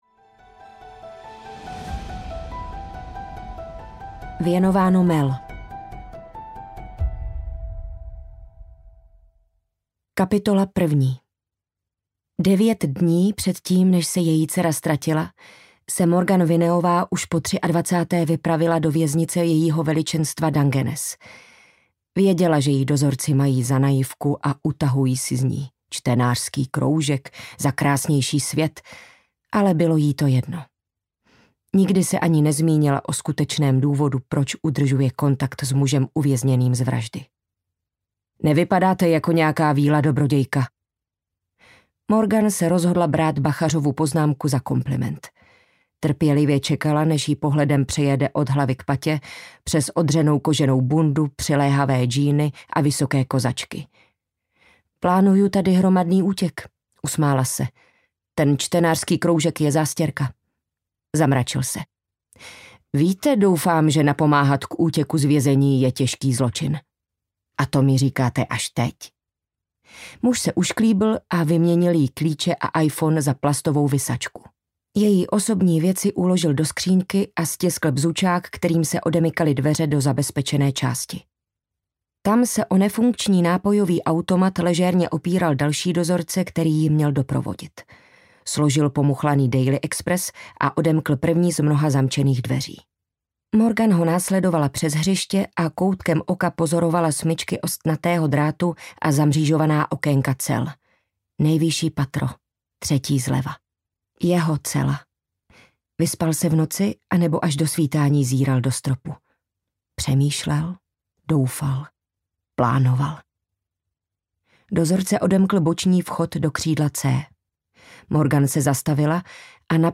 Stín pochybnosti audiokniha
Ukázka z knihy
• InterpretLucie Štěpánková